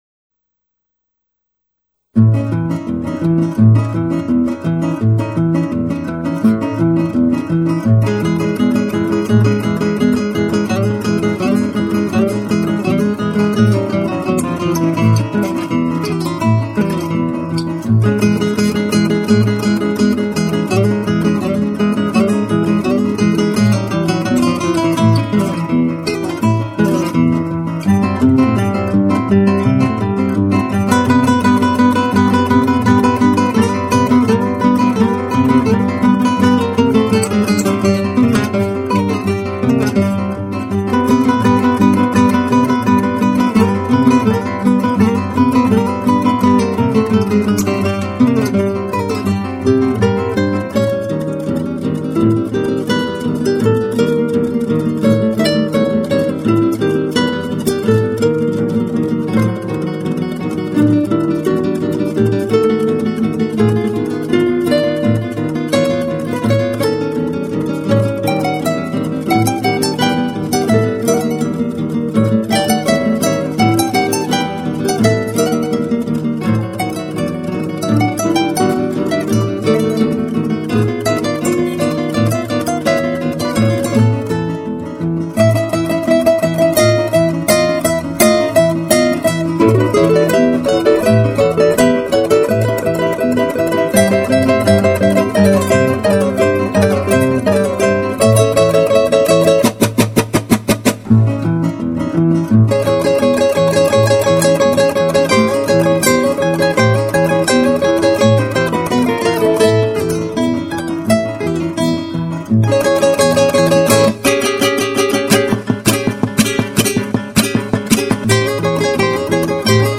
0163-吉他名曲黑色丹查.mp3